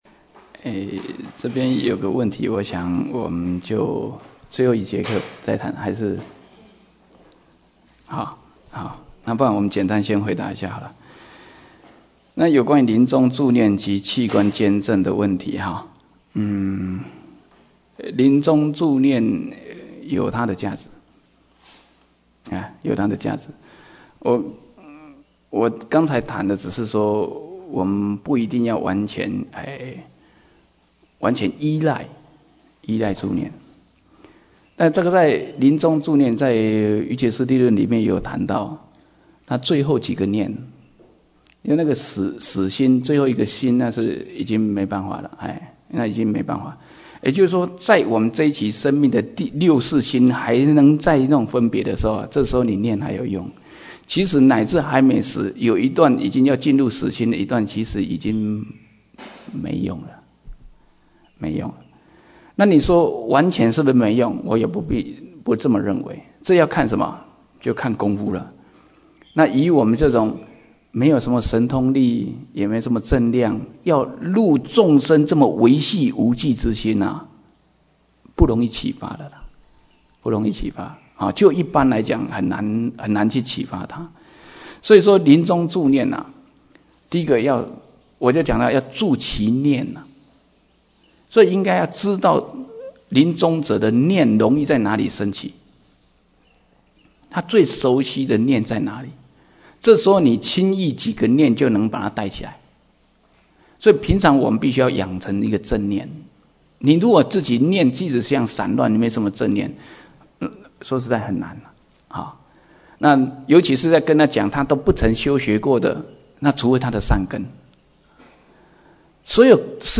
瑜伽师地论摄抉择分022(問答).mp3